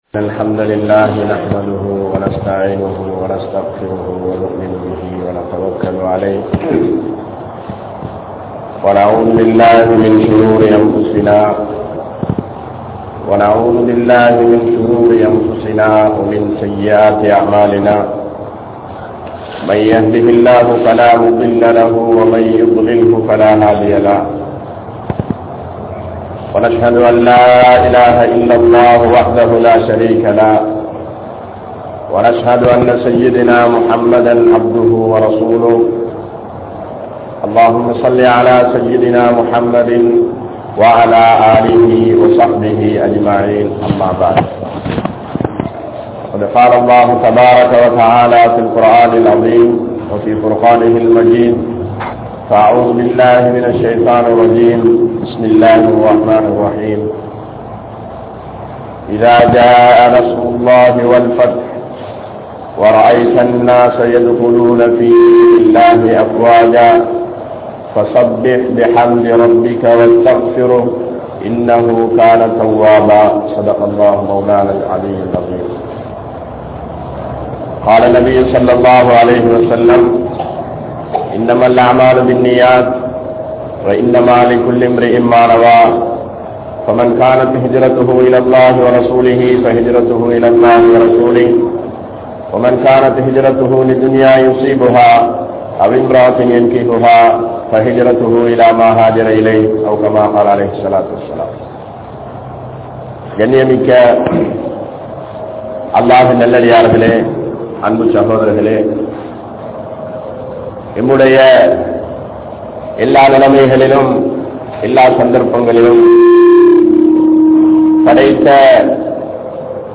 Muslimkale! Porumaiyaaha Vaalungal (முஸ்லிம்களே! பொறுமையாக வாழுங்கள்) | Audio Bayans | All Ceylon Muslim Youth Community | Addalaichenai
Mathurankadawela Jumua Masjidh